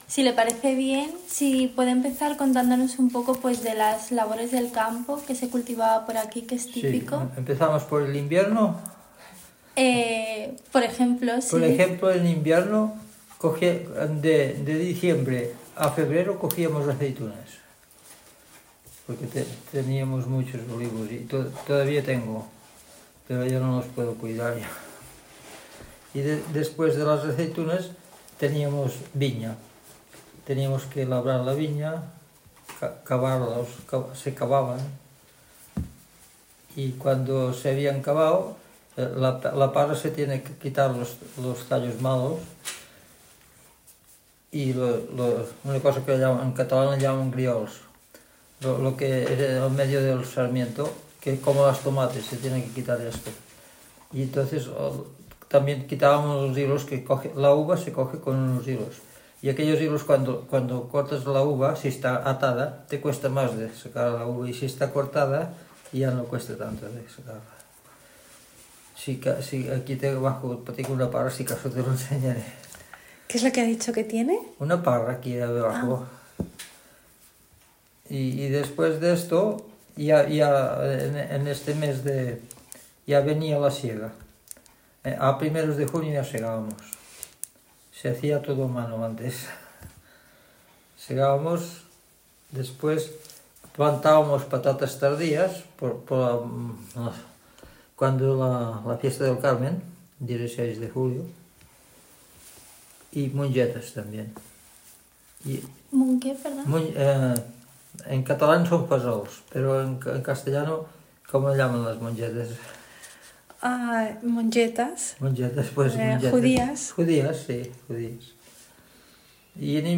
Locality Sant Mateu de Bages